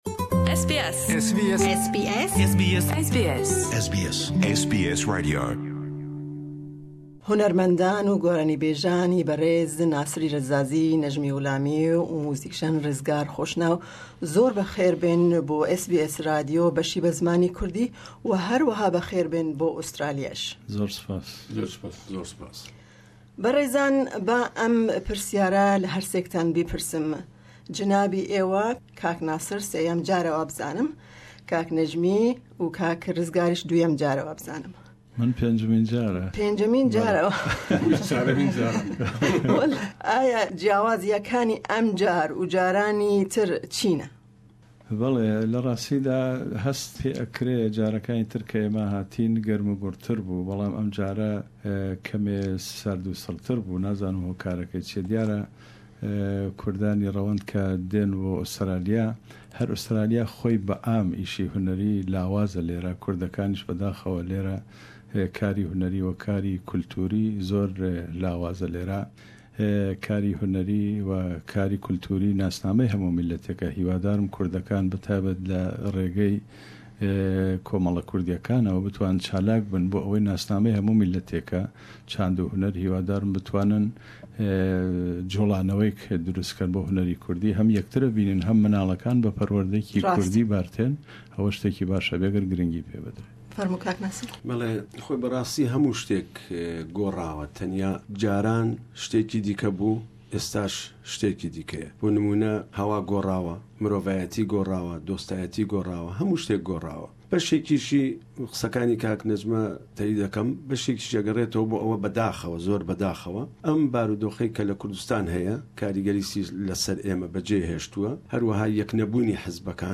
SBS Kurdish program invited them for an interview. We asked them about their visit and their thoughts of the Kurdish community in Australia. We attended their concert in Sydney.